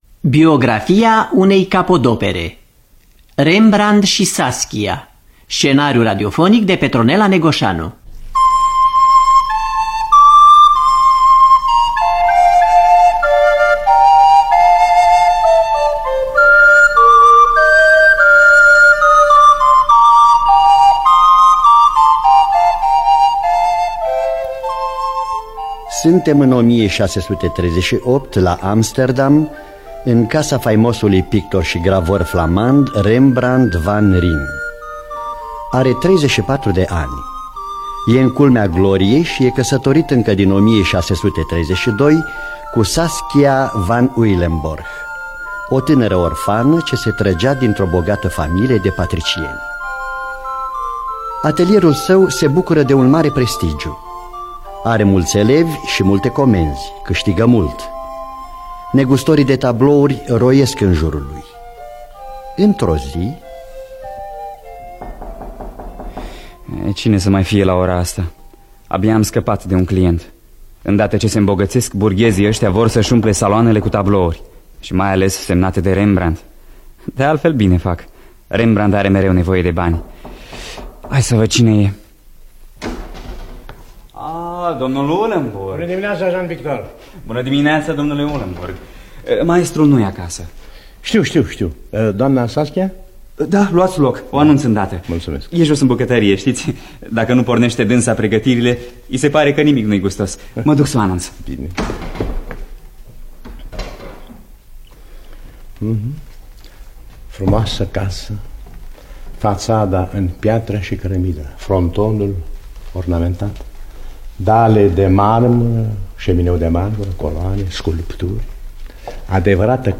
Biografii, memorii: Rembrandt și Saskia. Scenariu radiofonic